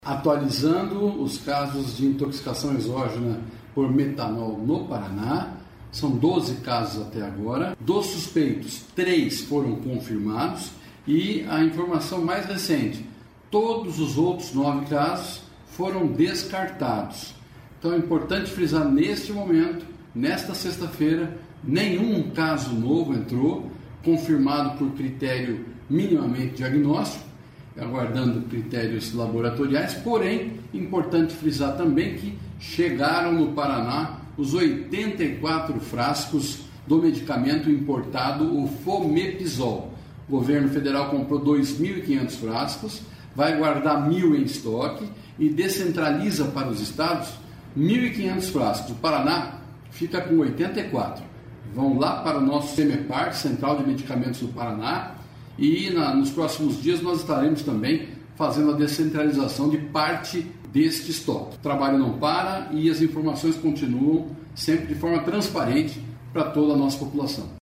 Sonora do secretário da Saúde, Beto Preto, sobre o descarte de novas intoxicações por metanol